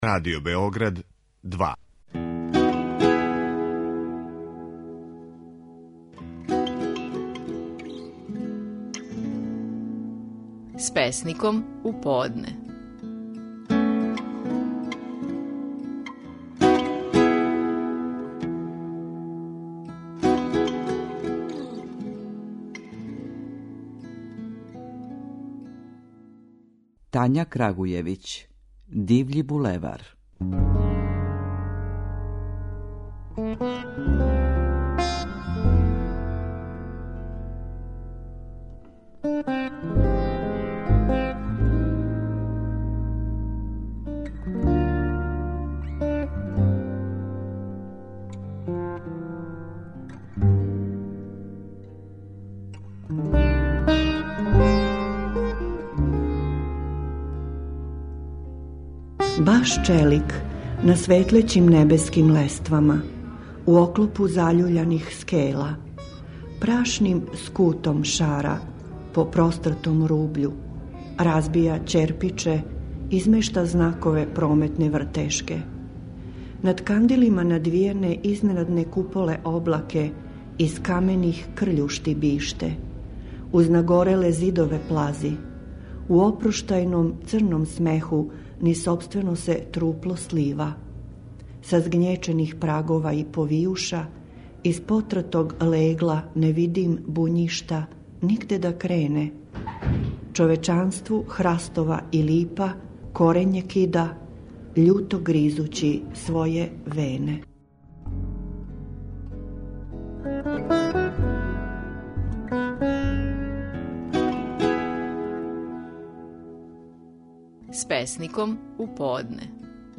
Песници говоре своје стихове